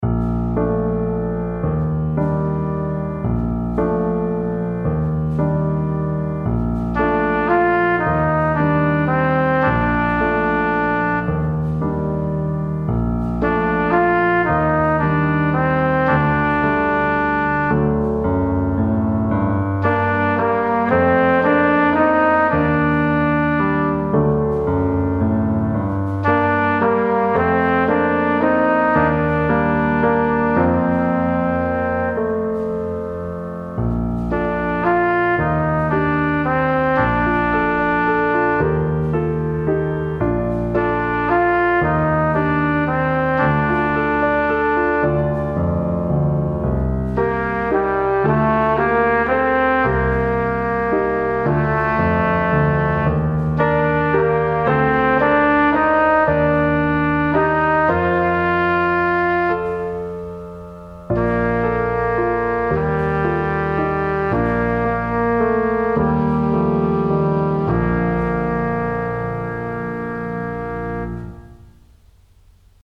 Performance